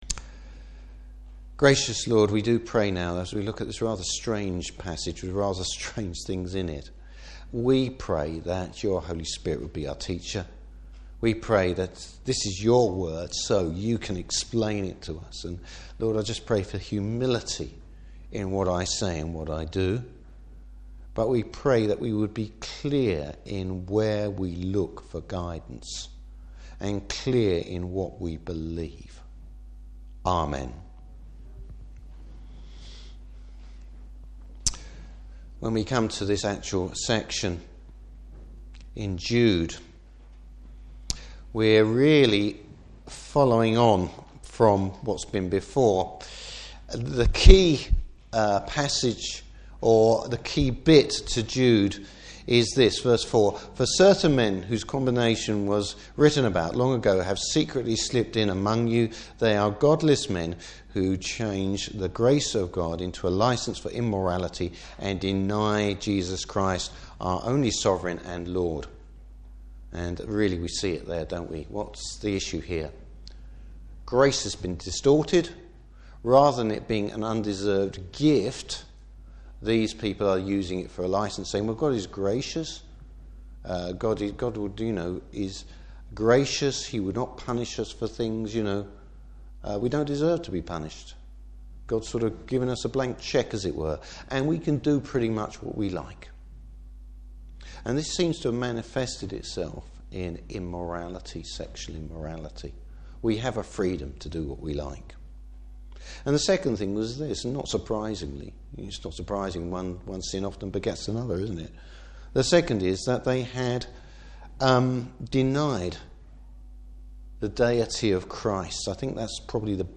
Service Type: Morning Service What gives false teachers away?